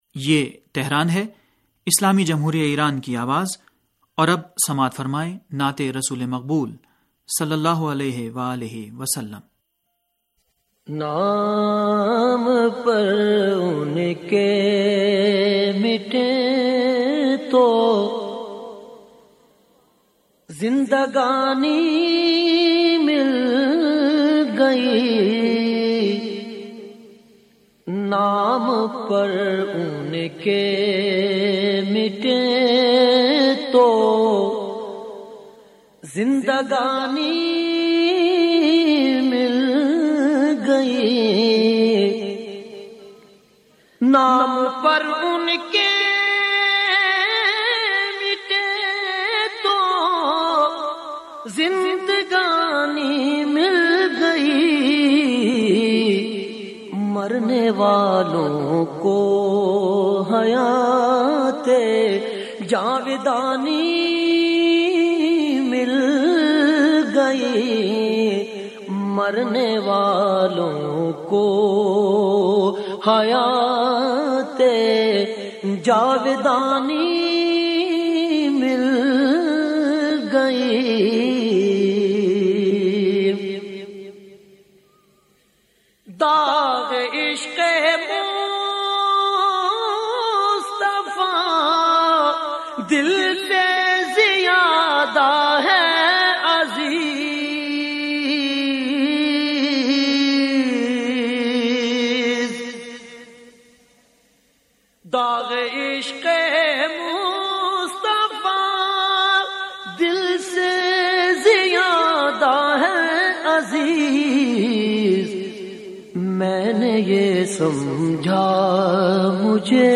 نعت رسول مقبول ص